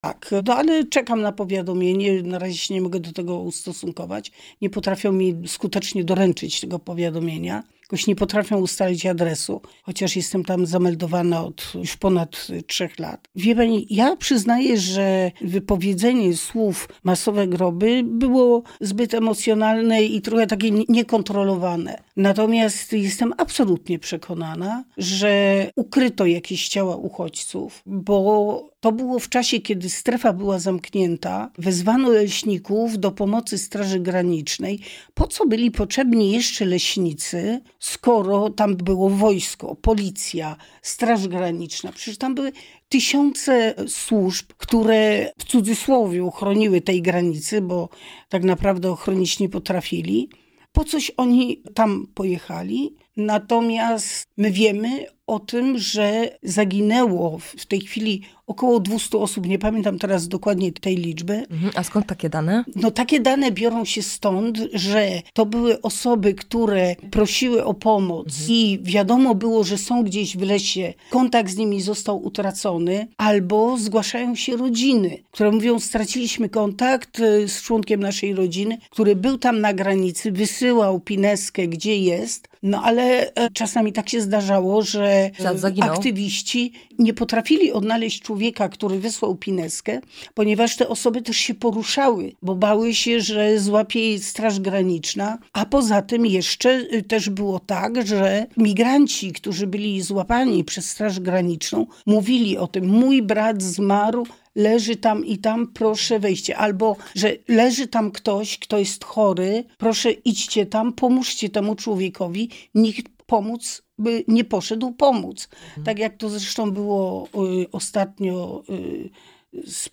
O swoich oczekiwaniach oraz przyszłości politycznej rozmawiamy na antenie Radia Rodzina.